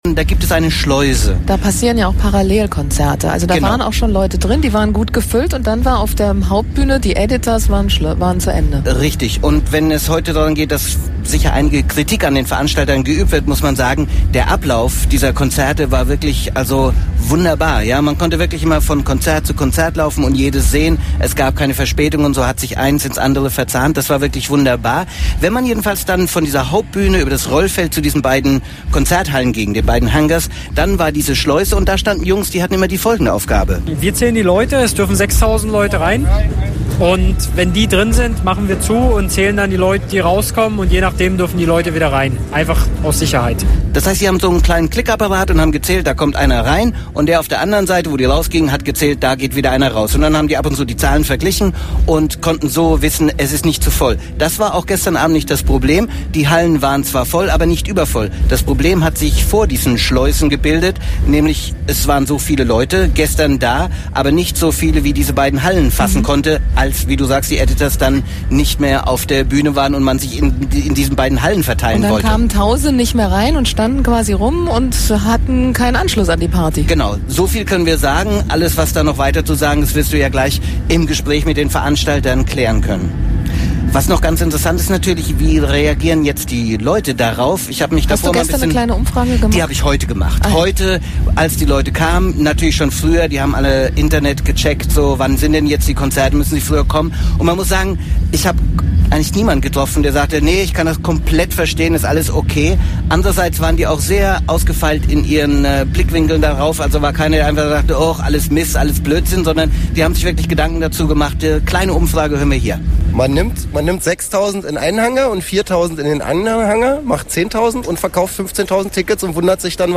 daß das reguläre programm an der größten bühne, der „main stage“, bereits gestern um 23h endete, hat eventuell etwas mit schnarchigen lärmschutzbestimmungen zu tun. hätte man „fatboy slim“ die hauptbühne bespielen lassen, wäre es eventuell nicht zu diesem abrupten ende gestern gekommen. (hier gibt es o-töne dazu vom publikum und den veranstaltern mittels „ radio eins “ als download )